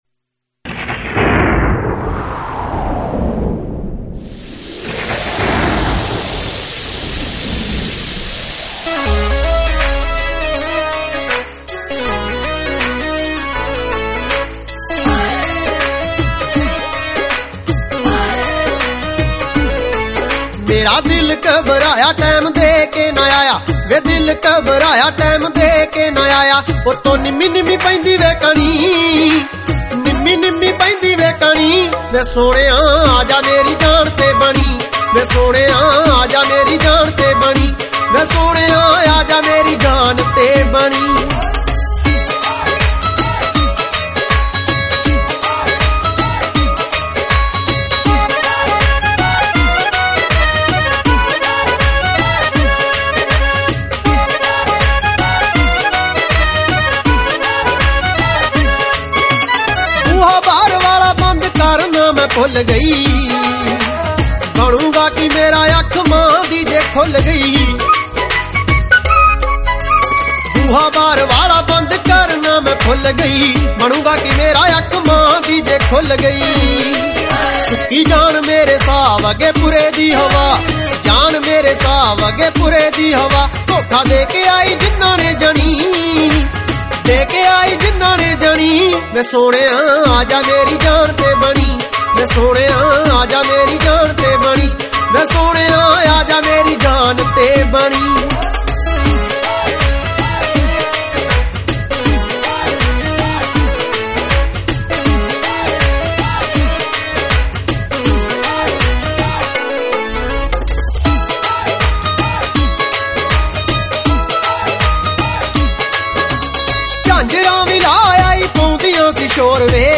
Punjabi Bhangra MP3 Songs
Low Quality